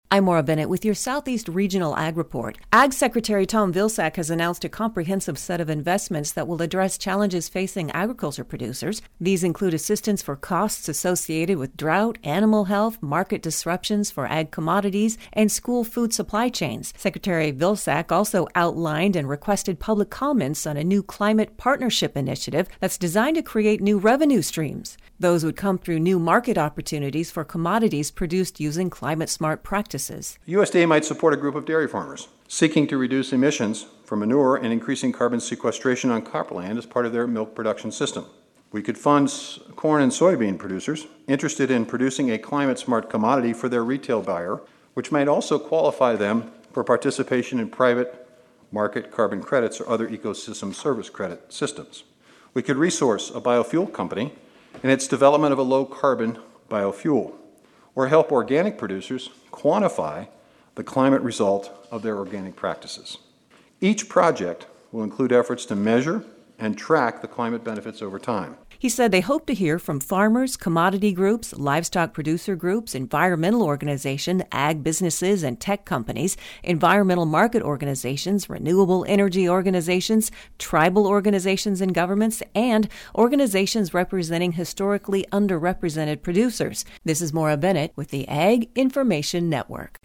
Vilsack speaking at the University of Colorado, added that USDA will take action to prevent the spread of African Swine Fever and other zootic diseases.